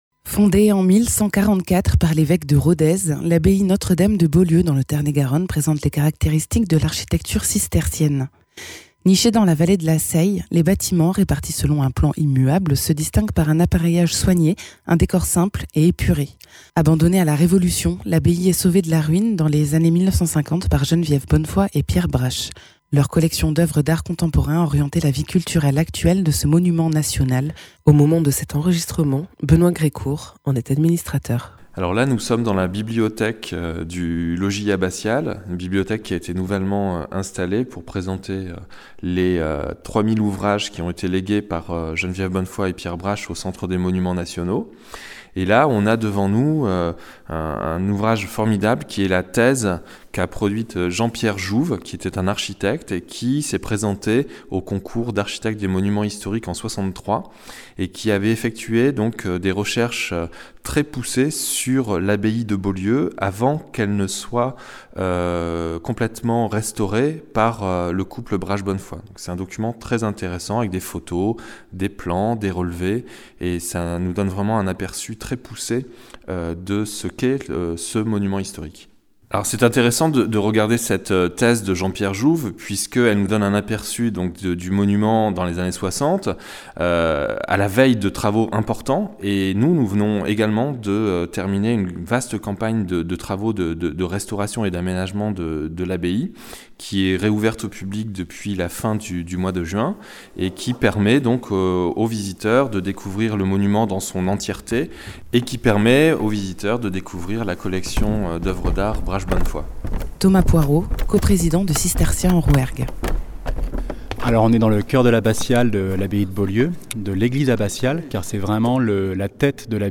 Architecture : reportage à Beaulieu avec les cisterciens du Rouergue
Interviews